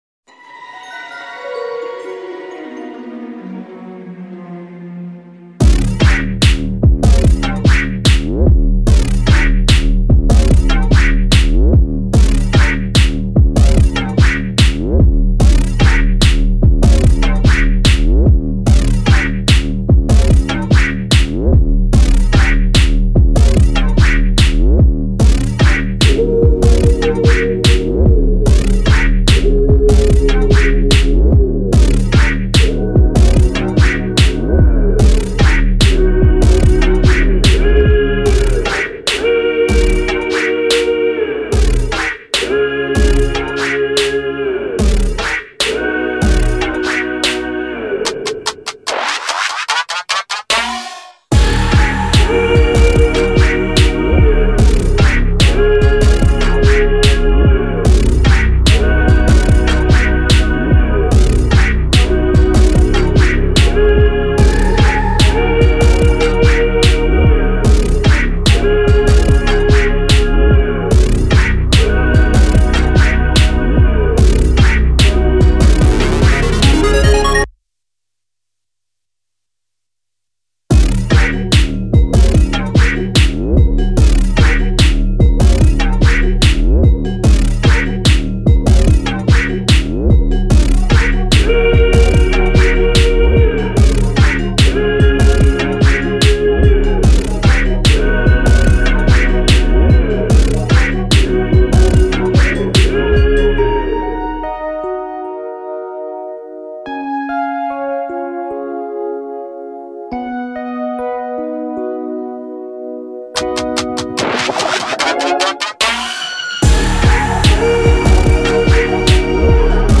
Pop Instrumentals